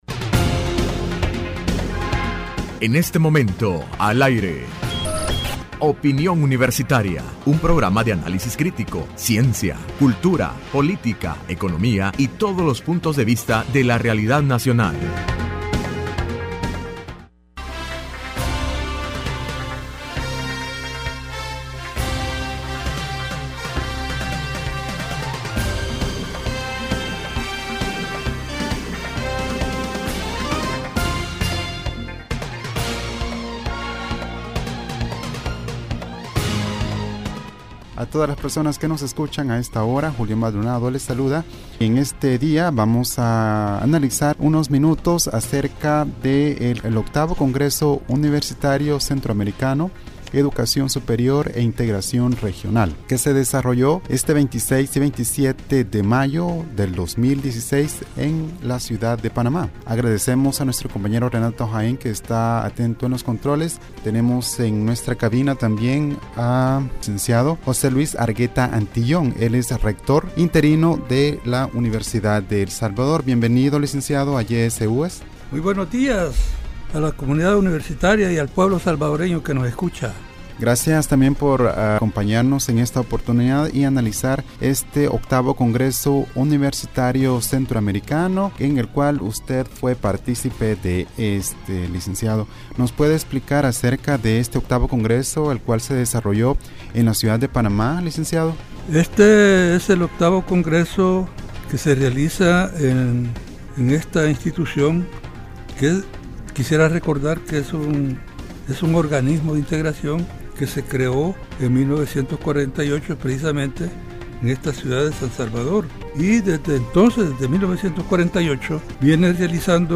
Entrevista Opinión Universitaria (2 de Junio 2016) : Resultados del VIII Congreso Universitario Centroamericano denominado:Educación Superior e Integración Regional realizado en Panamá.